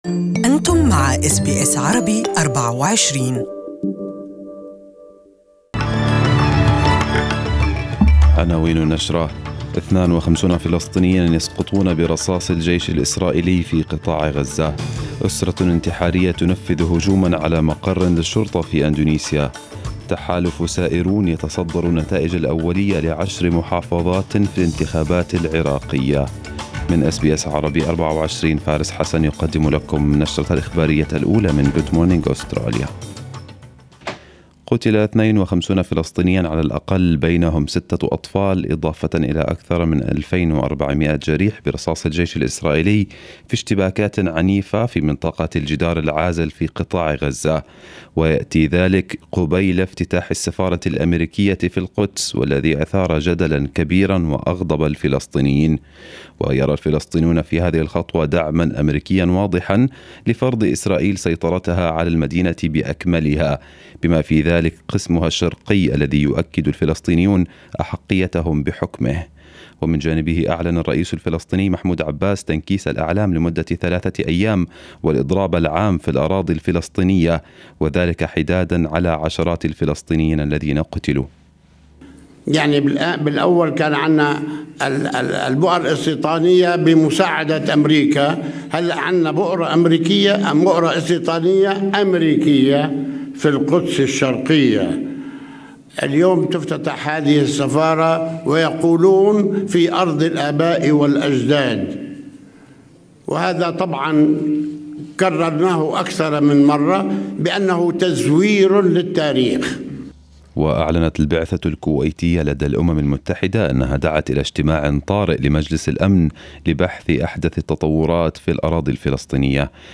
Arabic News Bulletin 15/05/2018